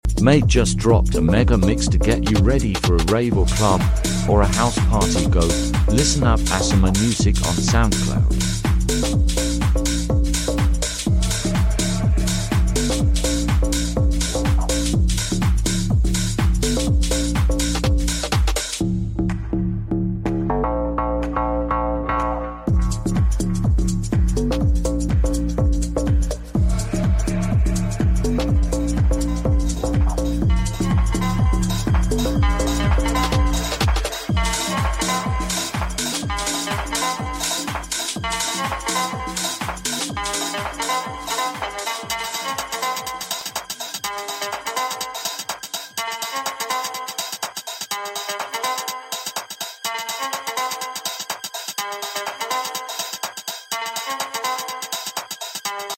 tech house tunes